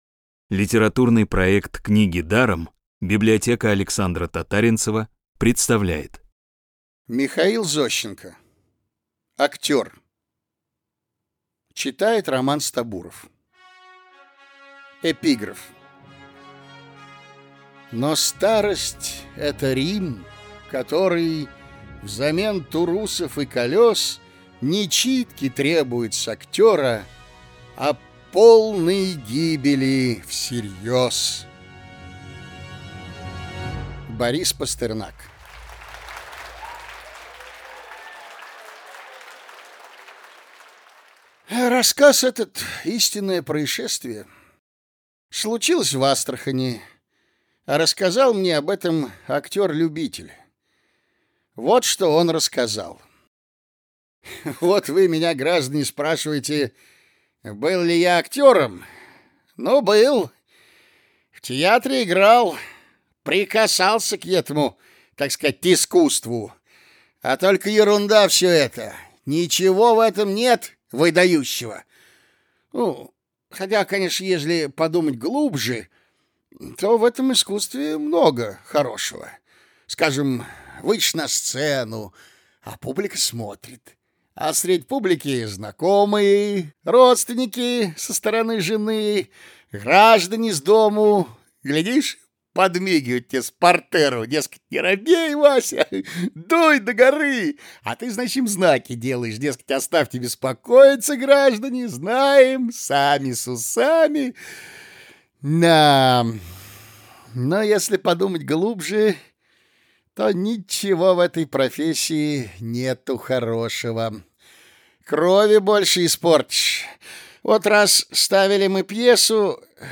«Книги даром» подготовили для вас аудиоверсию театральных рассказов Михаила Зощенко, таких, как: «Актёр», «Аристократка», «Мелкий случай», «Монтёр». Классическую литературу в озвучке «Рексквер» легко слушать благодаря профессиональной актерской игре и качественному звуку.